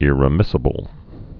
(ĭrĭ-mĭsə-bəl)